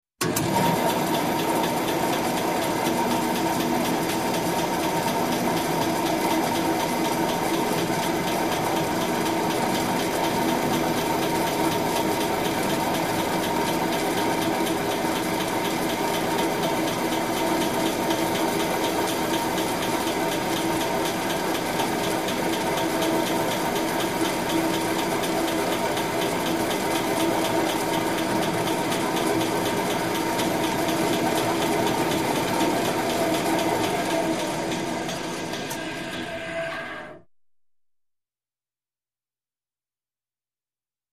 Band Saw | Sneak On The Lot
Motorized Shop Tool, Saw ( Band ); Turn On Steady, And Off With Wind Down.